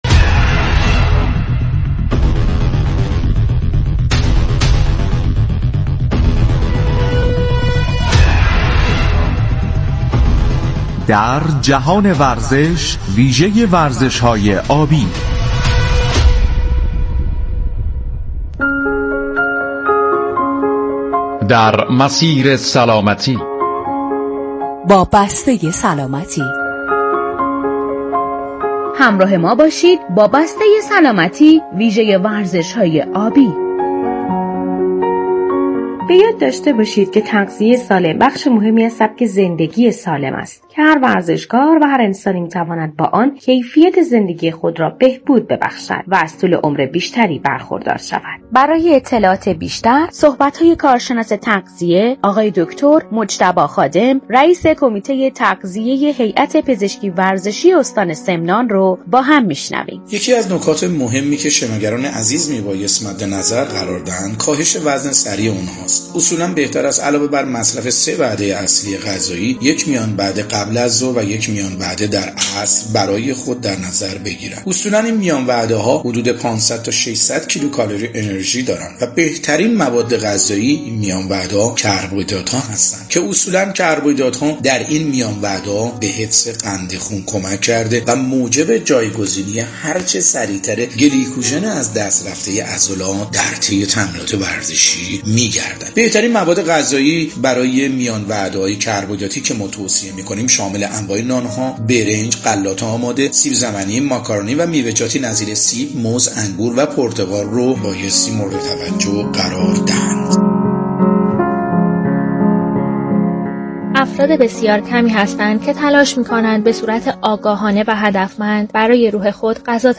برنامه جهان ورزش رادیو ورزش